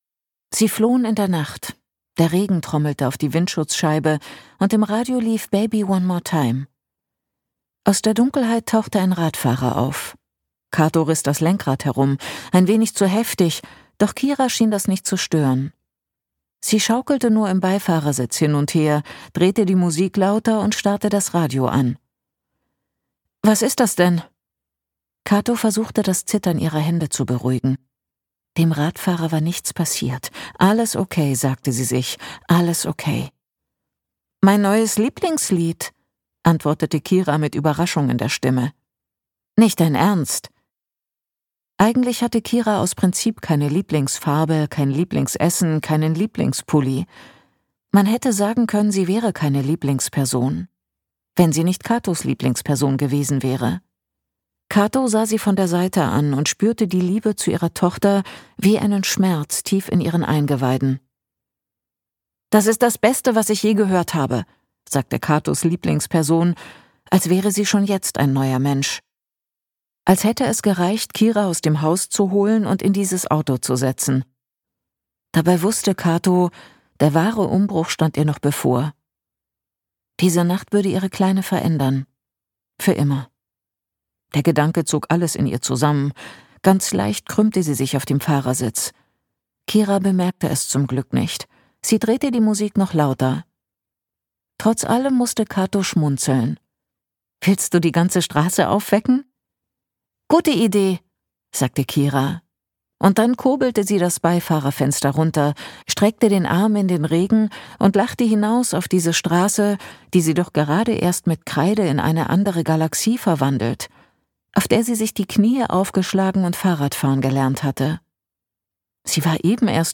Das neue Hörbuch der Autorin von »Stromlinien«
Gekürzt Autorisierte, d.h. von Autor:innen und / oder Verlagen freigegebene, bearbeitete Fassung.